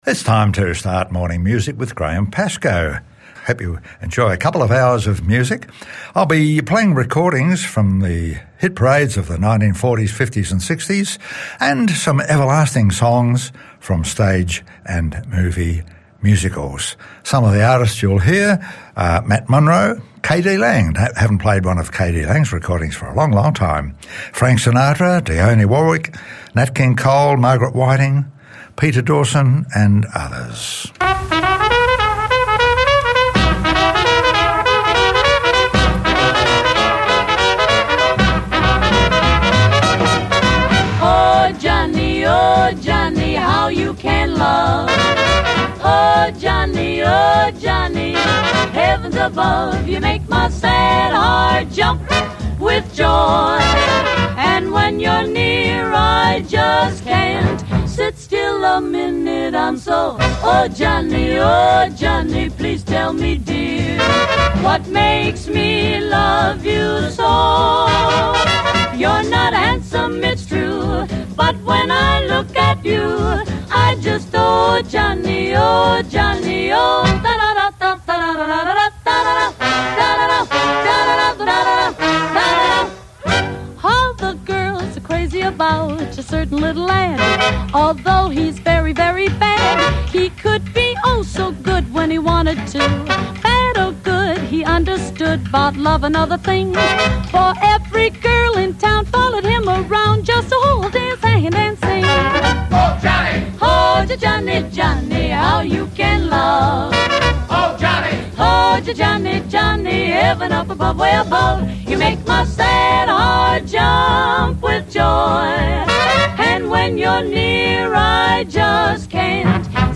popular music from pre rock & roll eras